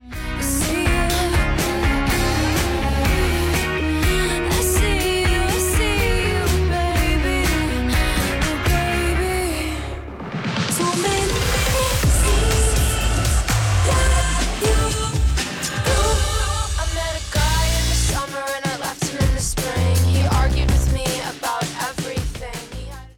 Indicatiu i tema musical